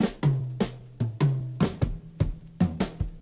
Revival Fill.wav